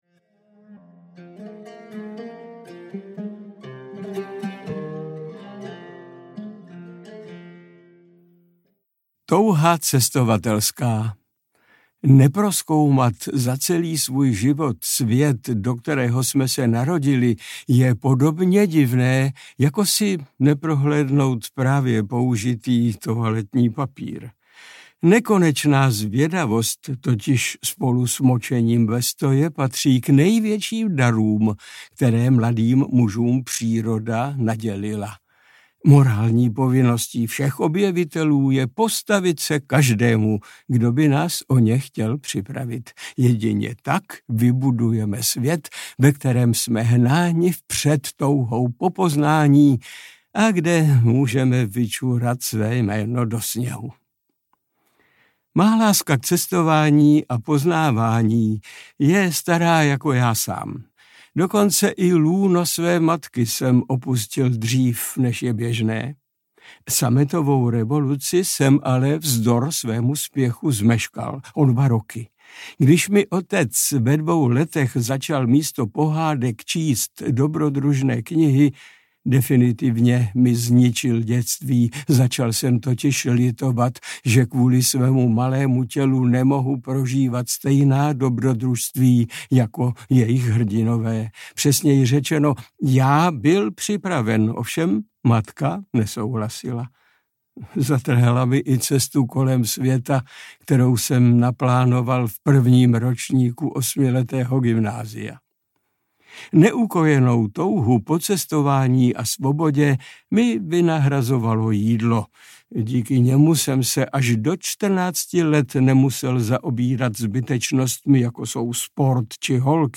40 dní pěšky do Jeruzaléma audiokniha
Ukázka z knihy
• InterpretMiloň Čepelka